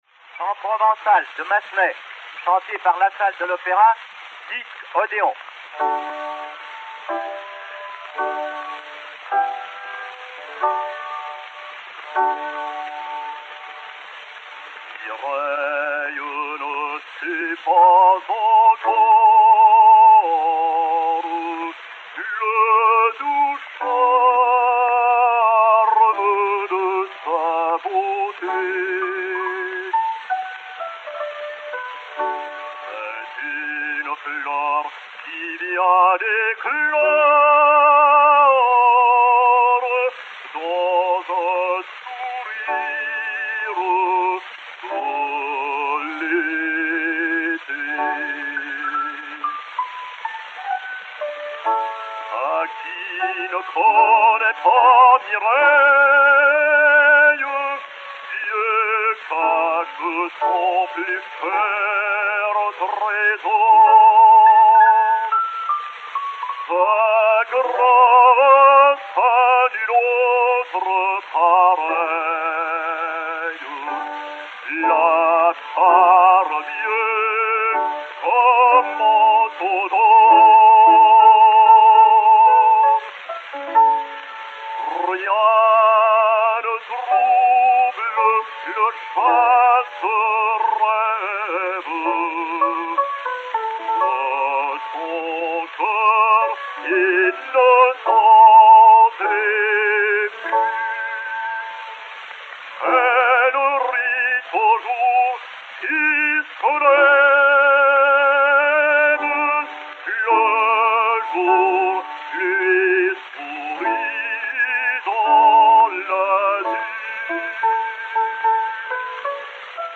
Jean-Louis Lassalle, baryton, avec piano
P 1043, enr. à Paris en 1906